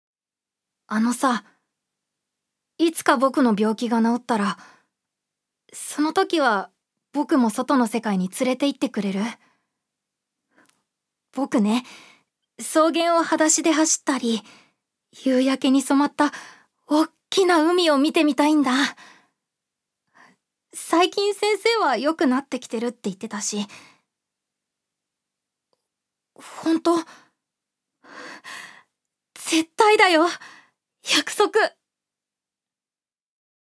女性タレント
セリフ４